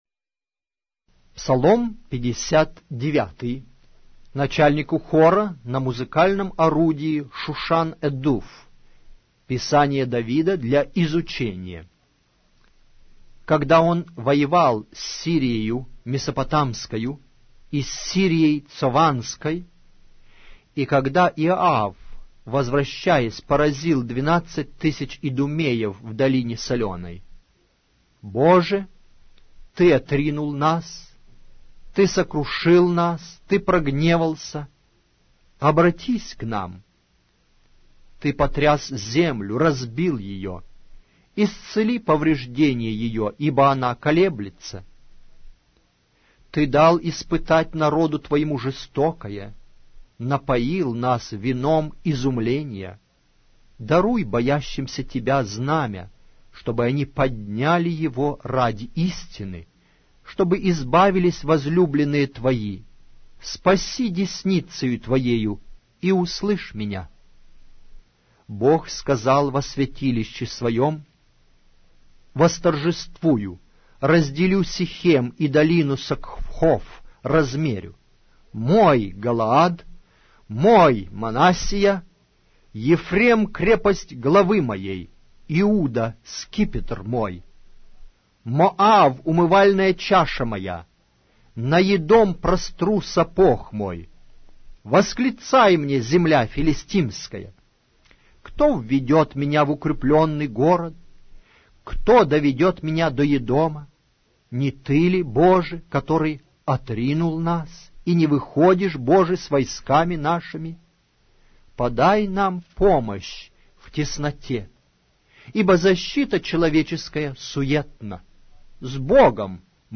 Аудиокнига: Псалтирь